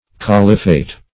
Caliphate \Cal"i*phate\, n. [Cf. F. califat.]